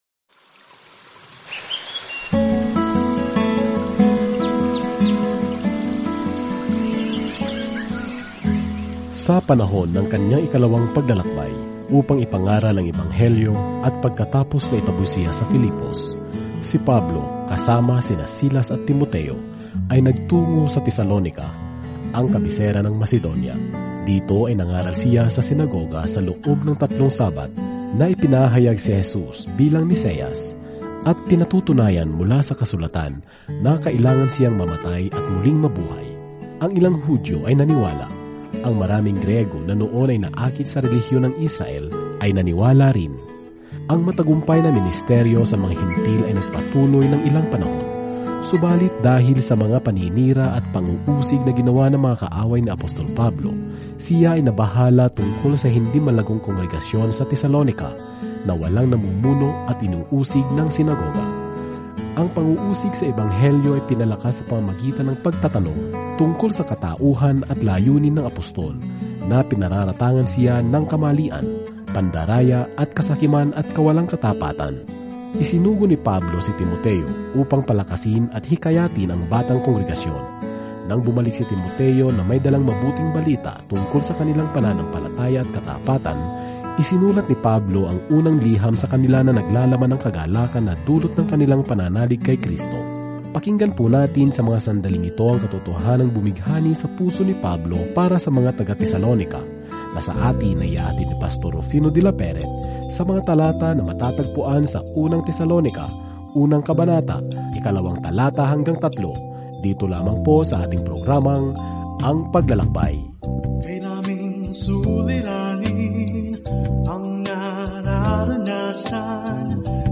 Araw-araw na paglalakbay sa 1 Tesalonica habang nakikinig ka sa audio study at nagbabasa ng mga piling talata mula sa salita ng Diyos.